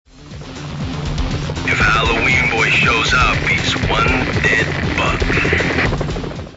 The movie scene is the same in both versions, except for the demo lacking the Spidey logo iris-in at the beginning and end, and one voice is different.
I remember being really pissed they changed it for the final, but in retrospect I can see why: that's one very bored reading.